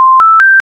scanner-beep-major-scale-up.c0feb14f.ogg